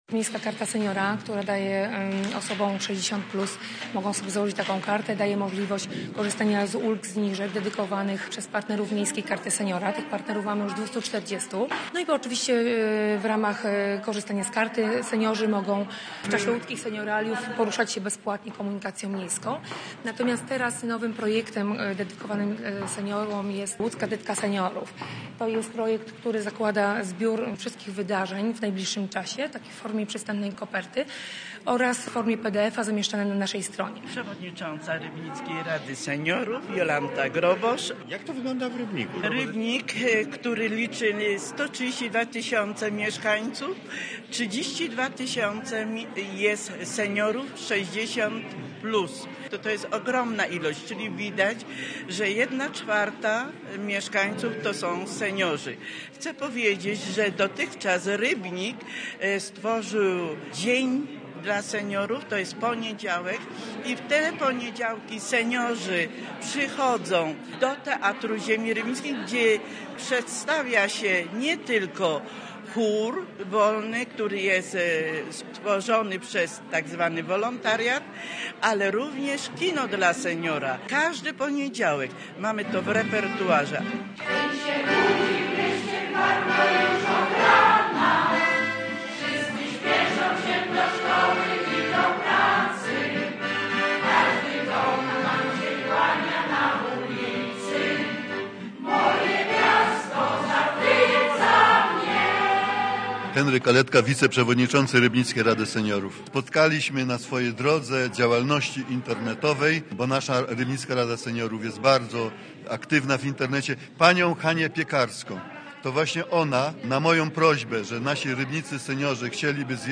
Posłuchaj relacji i dowiedz się więcej: Nazwa Plik Autor Seniorzy z Rybnika audio (m4a) audio (oga) Warto przeczytać W gminie Moszczenica powstanie odwiert geotermalny 3 lipca 2025 Dramatyczne chwile w piotrkowskim szpitalu.